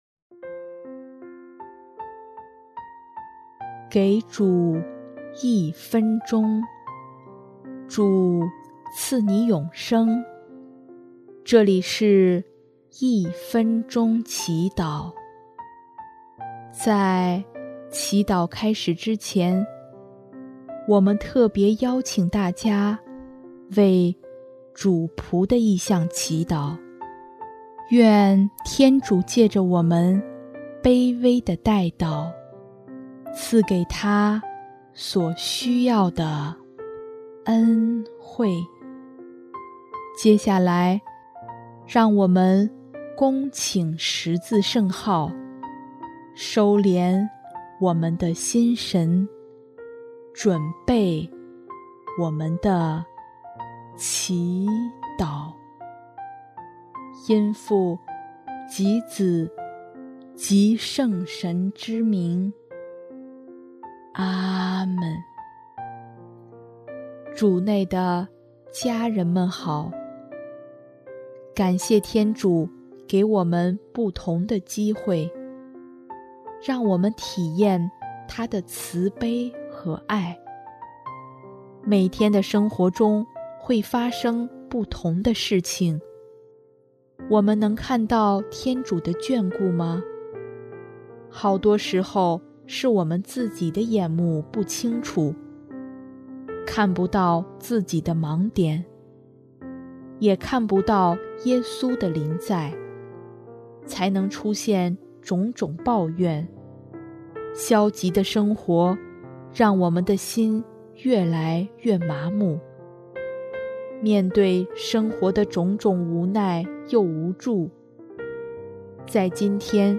【一分钟祈祷】|9月21日 跟随主必得眷顾